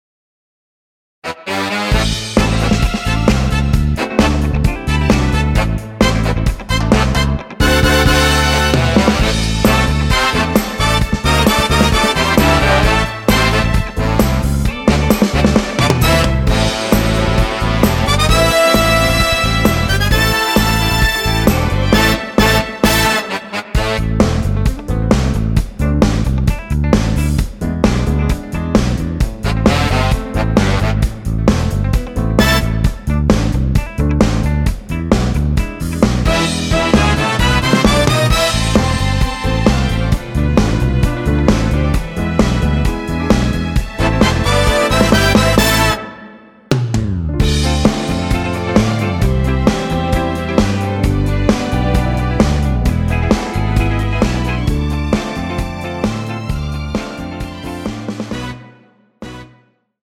MR입니다.
Am
앞부분30초, 뒷부분30초씩 편집해서 올려 드리고 있습니다.